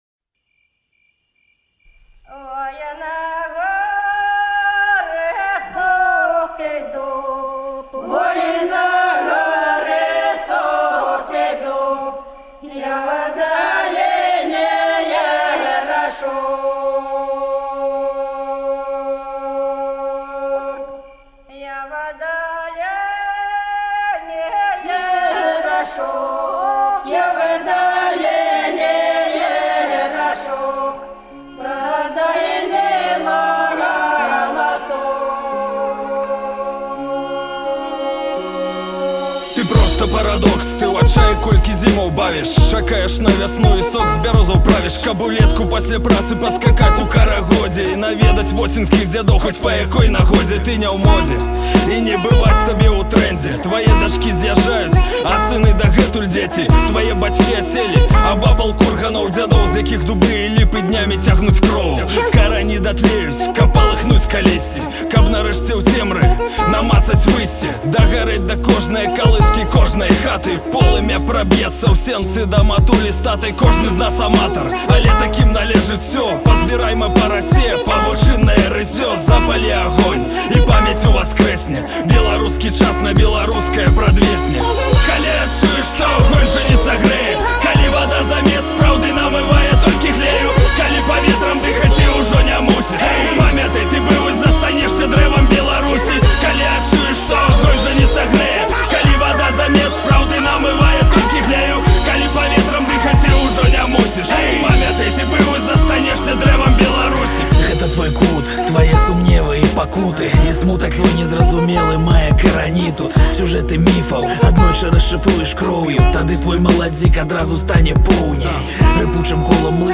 які аб’яднаў трох рэпэраў з розных рэгіёнаў краіны